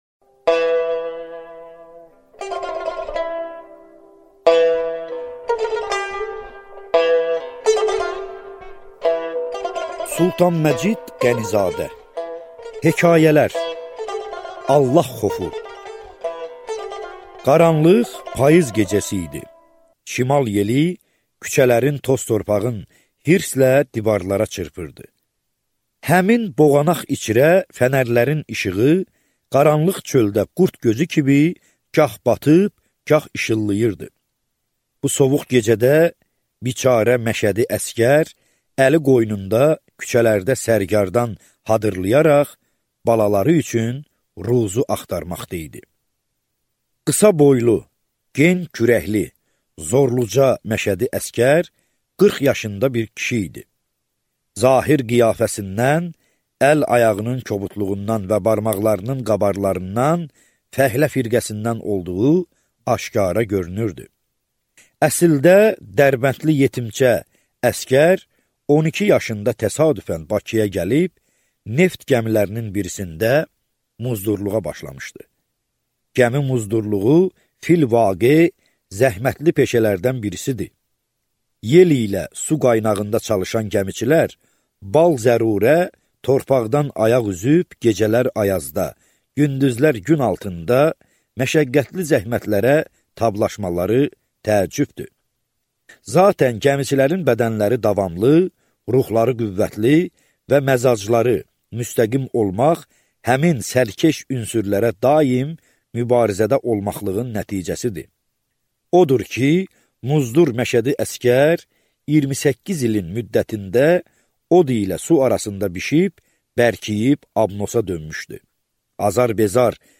Аудиокнига Hekayələr | Библиотека аудиокниг